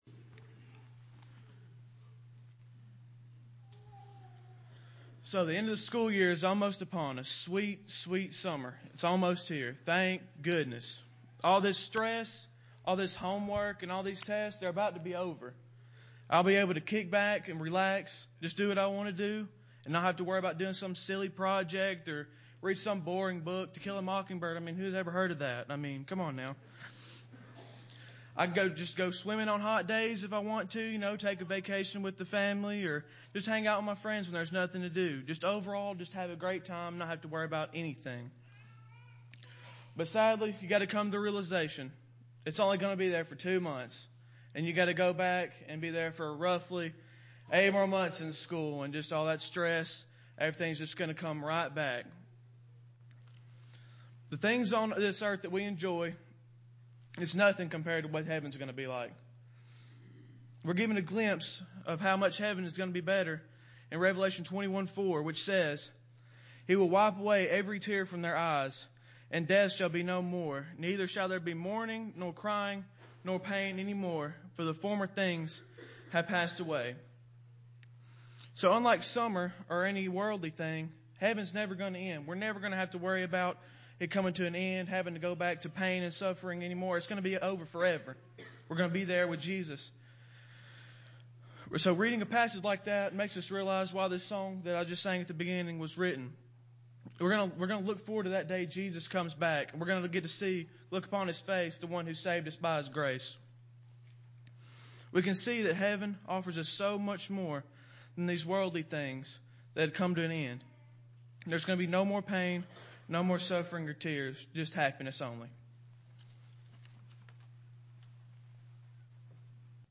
Young Men Led Service